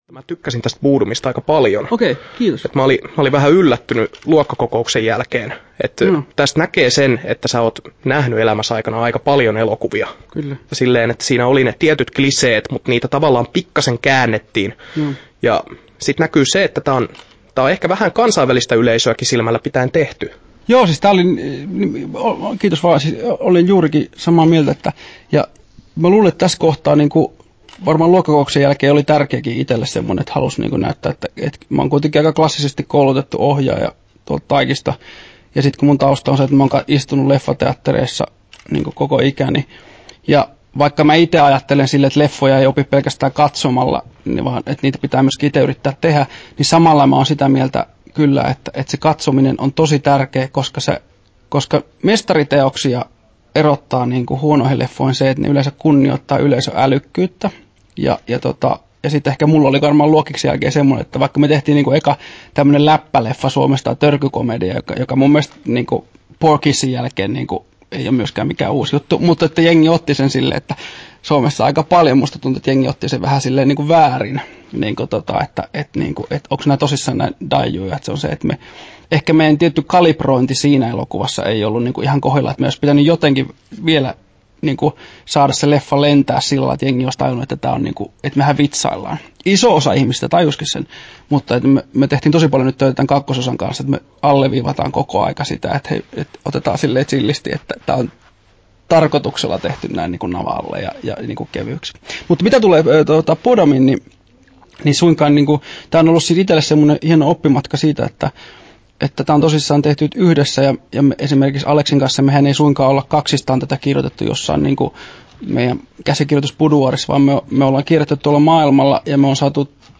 17'29" Tallennettu: 15.8.2016, Turku Toimittaja